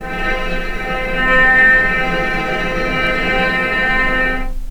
healing-soundscapes/Sound Banks/HSS_OP_Pack/Strings/cello/sul-ponticello/vc_sp-C4-pp.AIF at 61d9fc336c23f962a4879a825ef13e8dd23a4d25
vc_sp-C4-pp.AIF